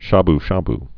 (shäb-shäb)